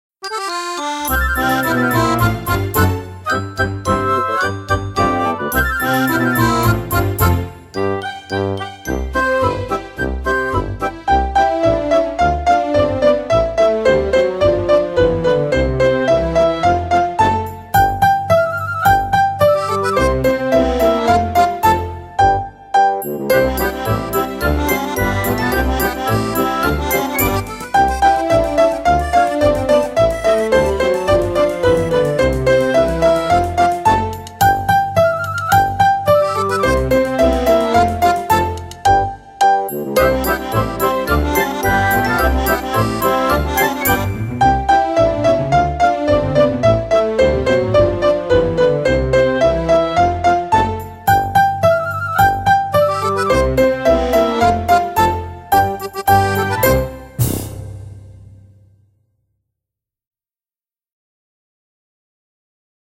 Właśnie my (wersja instrumentalna)